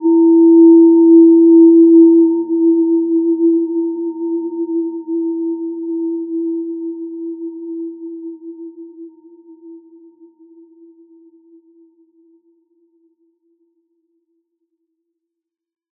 Gentle-Metallic-4-E4-p.wav